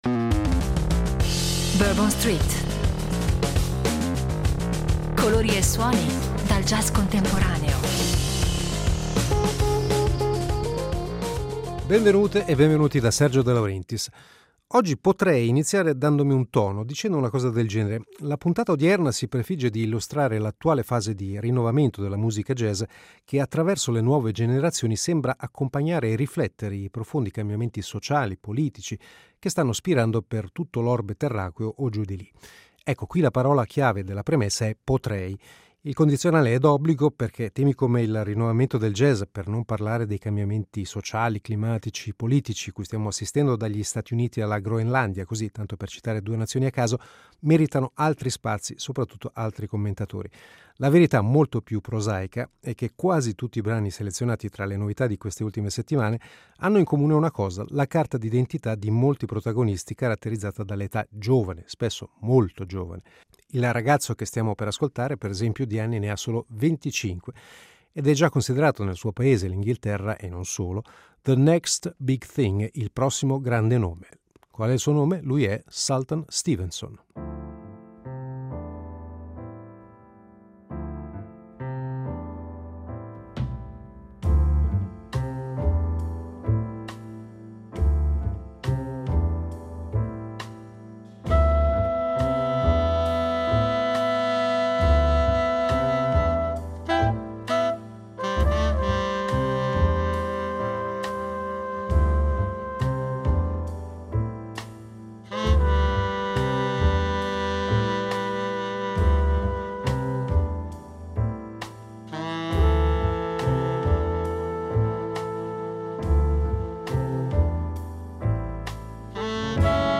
La forza rinnovatrice delle nuove generazioni nel Jazz